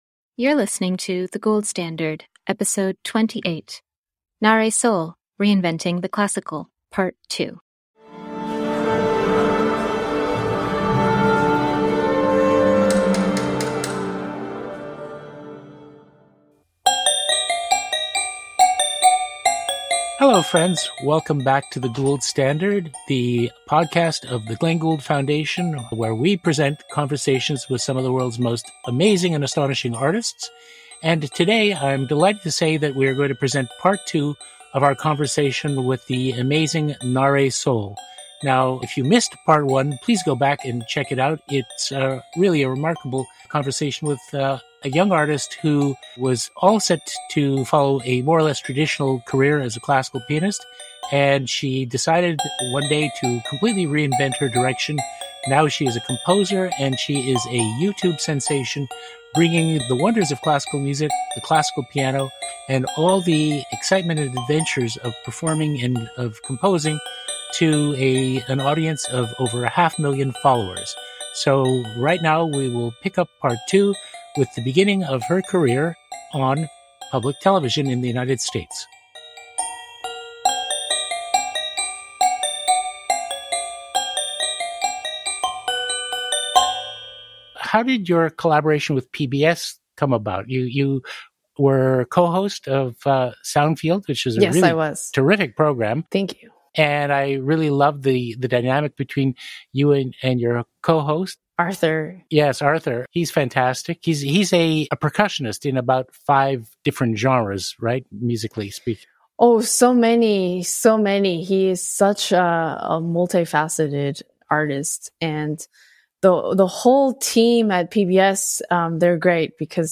In Part II of our conversation with pianist and YouTube sensation Nahre Sol, we explore how she creates her hugely successful online content. Nahre also dives deep into her stint as a PBS cohost, the creative process underpinning her debut album, and the triumphs and tribulations involved in her daily life as an artist and creator.